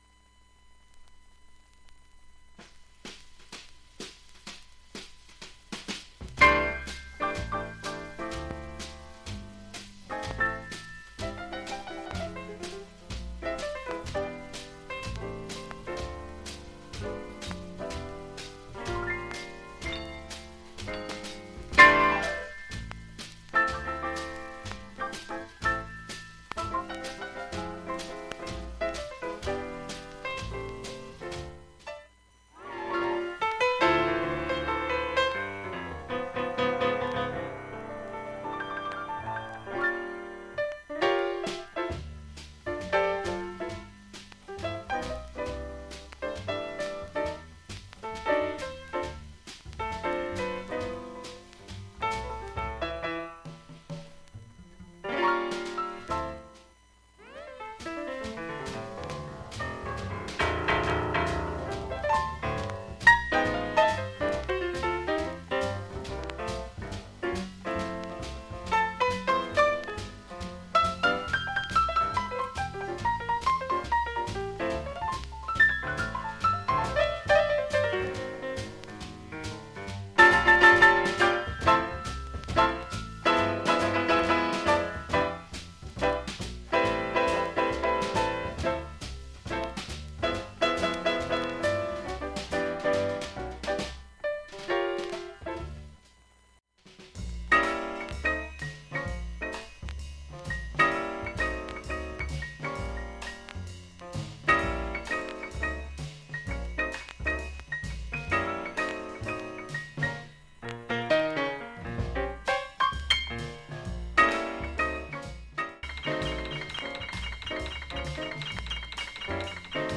普通に聴けます全曲試聴済み。
現物の試聴（上記録音時間4分弱）できます。音質目安にどうぞ
◆ＵＳＡ盤 Reissue, Stereo